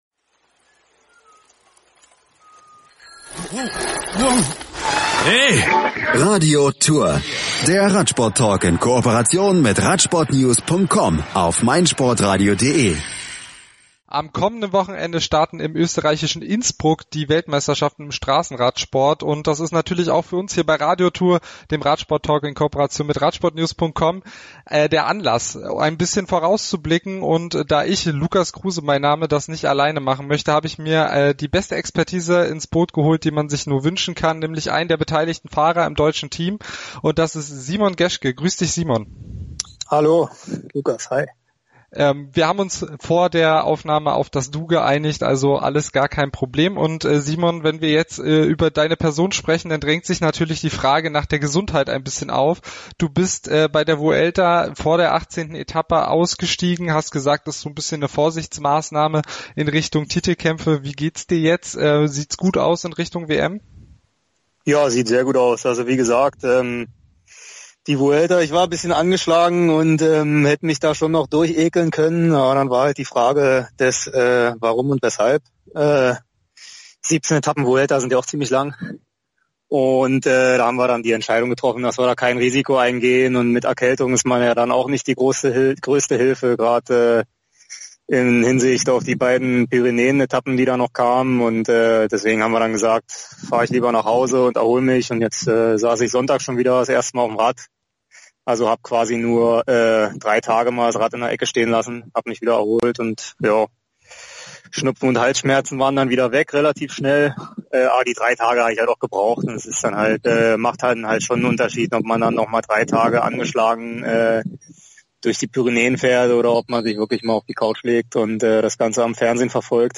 Im Interview berichtet er unter anderem von seiner Vorbereitung und den Abläufen innerhalb des deutschen Teams. Zudem verrät er seine persönlichen Favoriten und schildert die Besonderheiten eines WM-Rennens.